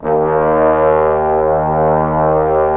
Index of /90_sSampleCDs/Roland LCDP06 Brass Sections/BRS_Bs.Trombones/BRS_Bs.Bone Sect